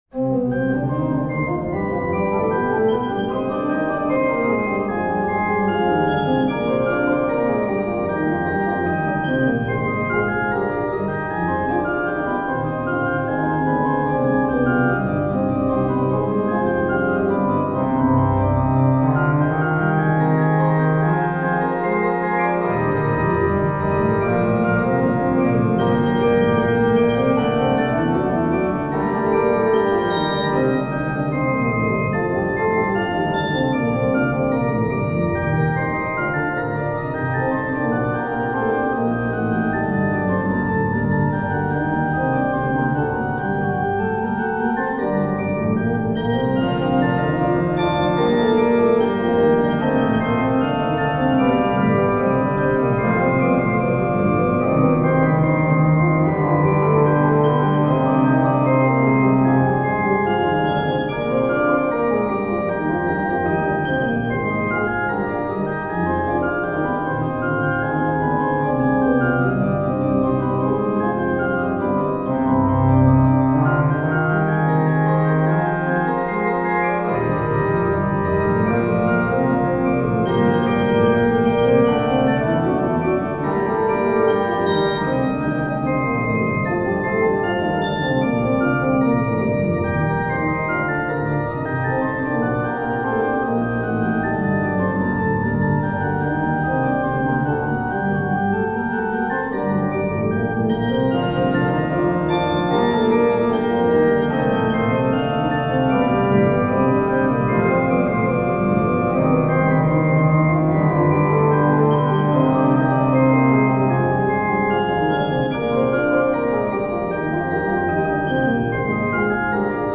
Elles montrent même une recherche contrapuntique complexe.